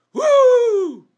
w_cheer2.wav